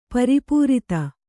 ♪ pari pūrita